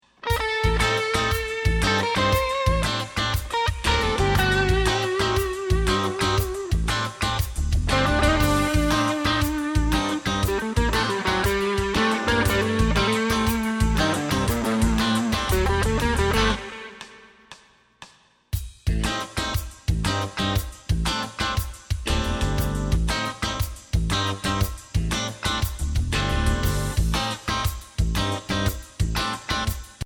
Voicing: Guitar w/c